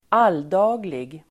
Uttal: [²'al:da:glig]